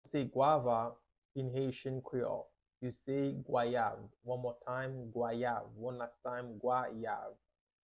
How to say “Guava” in Haitian Creole – “Gwayav” pronunciation by a native Haitian Teacher
How-to-say-Guava-in-Haitian-Creole-–-Gwayav-pronunciation-by-a-native-Haitian-Teacher.mp3